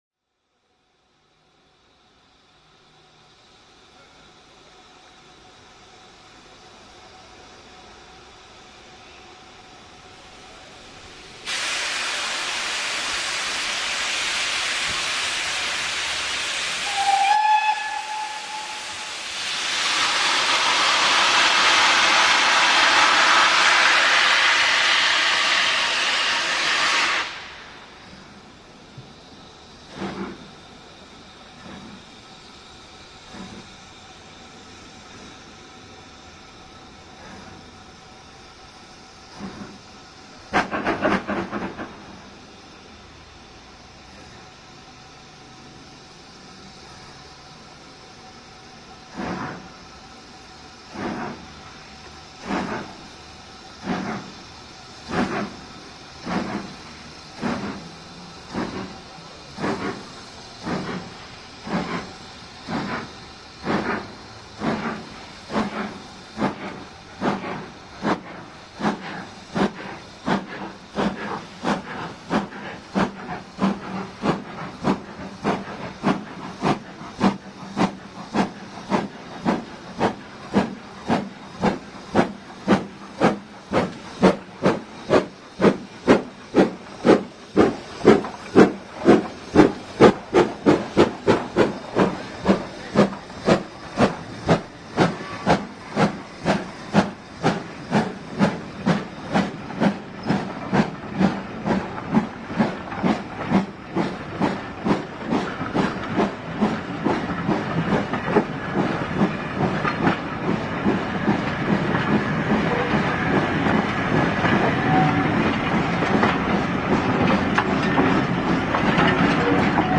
The start out of Keighley must be one of the most difficult starts on a preserved line in the UK with its combination of a steep gradient on a sharp curve. Fortunately, experience has proved that with correct handling by the loco crew, it needn't be a problem as demonstrated by the driver of BR Standard Class 4 2-6-4T 80002 as it leaves for Oxenhope with 6 coaches behind the bunker. 80002 departing from Keighley. 14th October 2006 3:42 One of the railways longest serving locos is the Ivatt Class 2 2-6-2T 41241 which along with 30072 worked the re-opening special back in 1968.